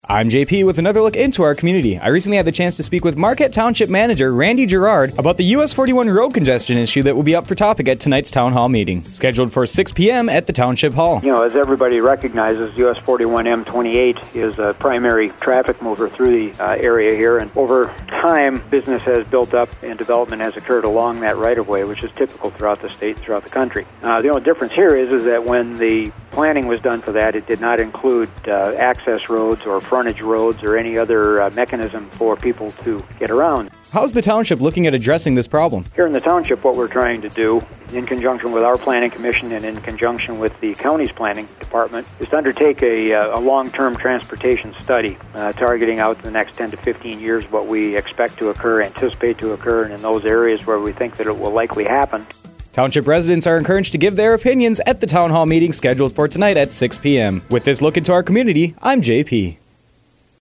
Interview – Marquette Township Manager, Randy Girard – US 41 Congestion